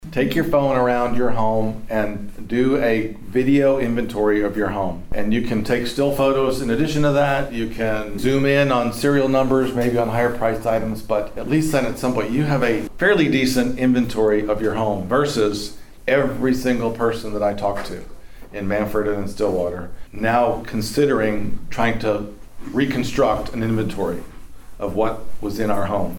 Oklahoma Insurance Commissioner Speaks in Pawhuska
The Pawhuska Chamber of Commerce hosted the April lunch and learn series, which featured Oklahoma Insurance Commissioner Glen Mulready as the speaker.